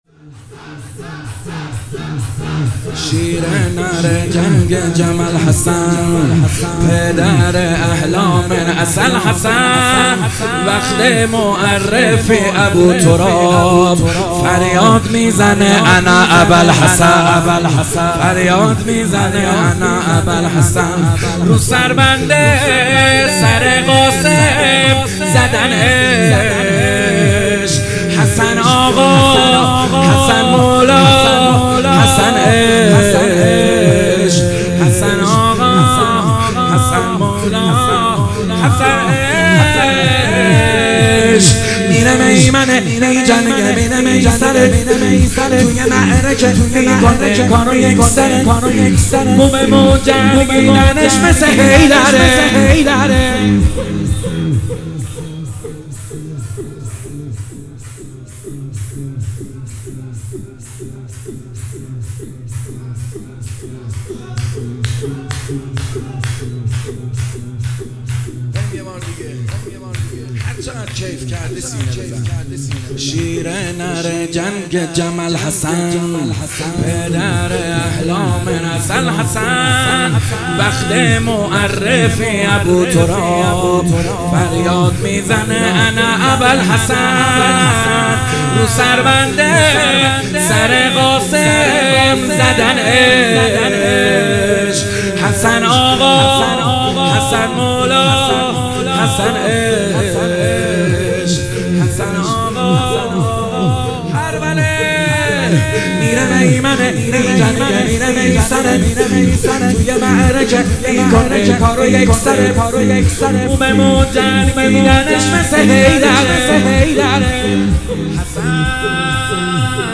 مداحی شب پنجم محرم